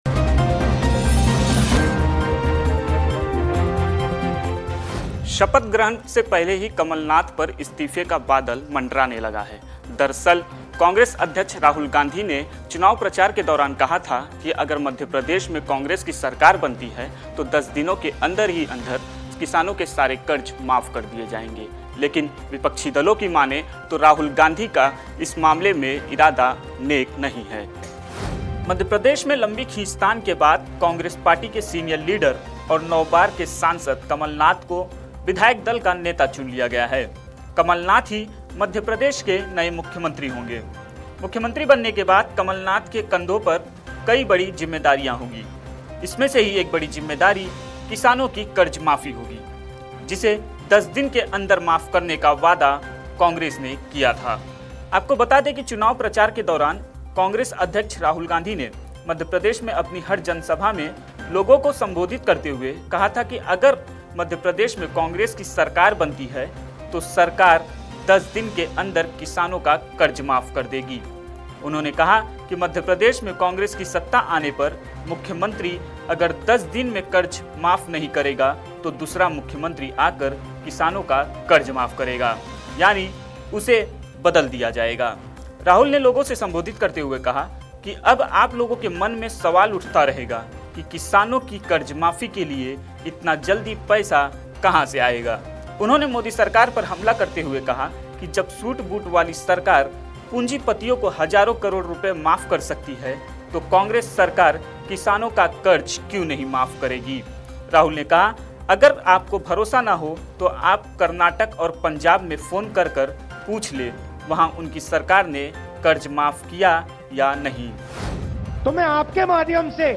न्यूज़ रिपोर्ट - News Report Hindi / दस दिन में कमलनाथ को देना पड़ सकता है इस्तीफा